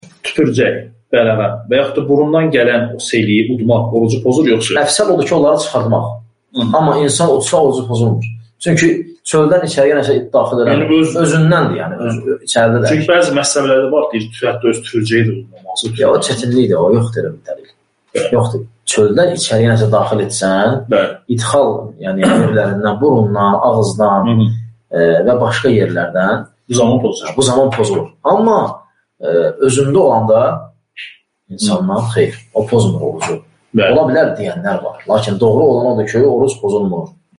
Sual-cavab